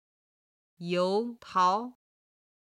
軽声の音は音源の都合上、四声にて編集しています。
油桃　(yóu táo)　ネクタリン
23-you2tao2.mp3